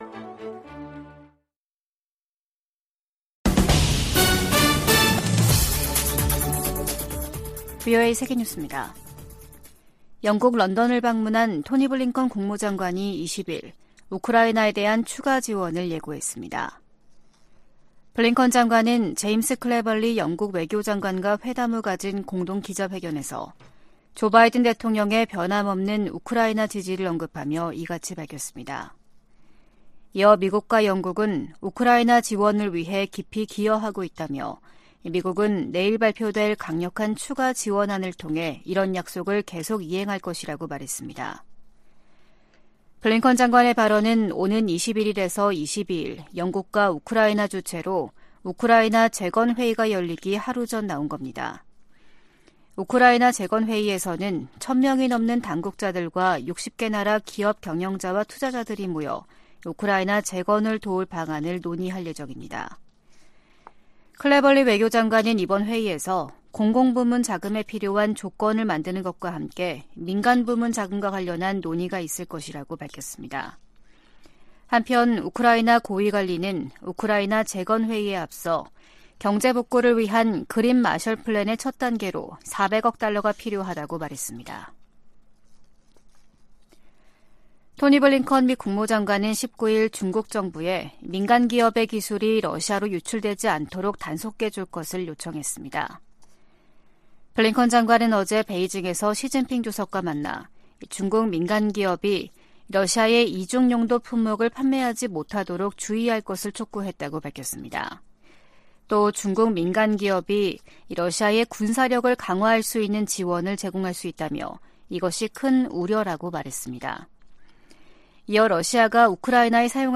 VOA 한국어 아침 뉴스 프로그램 '워싱턴 뉴스 광장' 2023년 6월 21일 방송입니다. 베이징을 방문한 블링컨 미 국무장관은 중국에 북한이 도발을 멈추고 대화 테이블로 나오도록 영향력을 행사해 줄 것을 촉구했습니다. 미 국방부 콜린 칼 차관의 최근 일본 방문은 미일 동맹의 진전을 보여주는 것이었다고 미 국방부가 평가했습니다. 한국 군은 대북 무인기 작전 등을 주요 임무로 하는 드론작전사령부를 오는 9월 창설할 예정입니다.